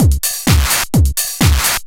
DS 128-BPM A7.wav